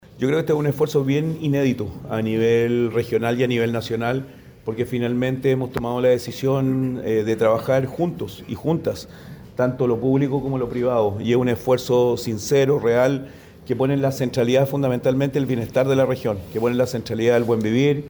Tras la solemne ceremonia de firma, el gobernador Rodrigo Mundaca, se refirió a la importancia de la firma de este acuerdo, para la que, según sus palabras, es la región más importante del país.